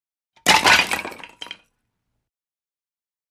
IMPACTS & CRASHES - WOOD WOOD: EXT: Dropping small piles of wood.